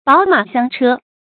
寶馬香車 注音： ㄅㄠˇ ㄇㄚˇ ㄒㄧㄤ ㄔㄜ 讀音讀法： 意思解釋： 華麗的車子，珍貴的寶馬。指考究的車騎。